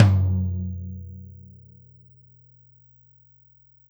Tom Shard 10.wav